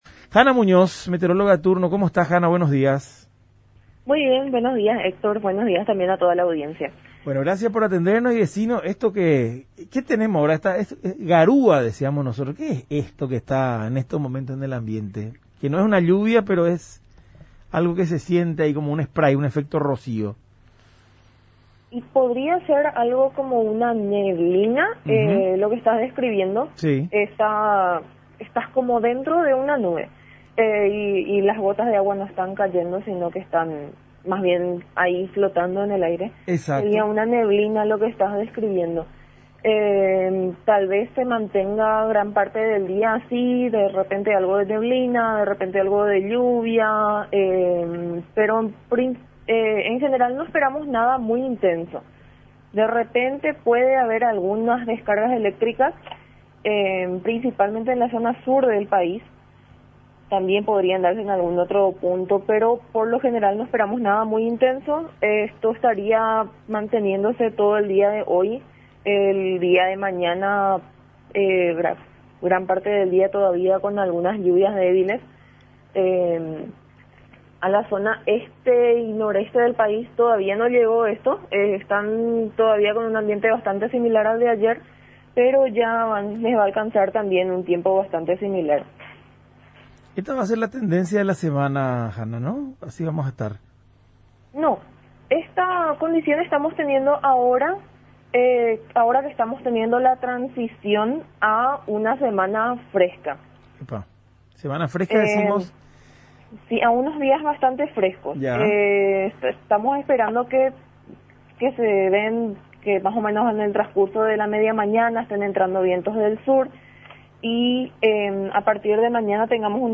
pronosticadora de turno